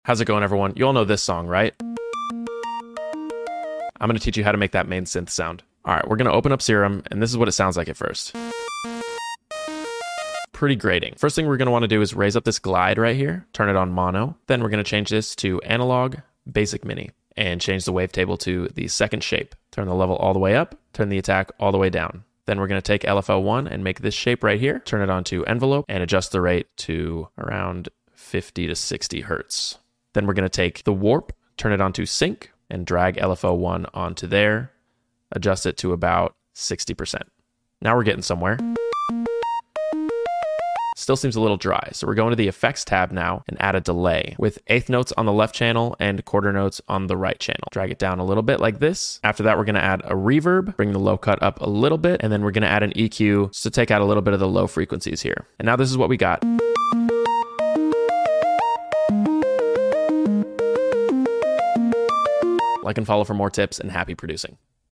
how to recreate the synth sound from owl city’s “fireflies” in serum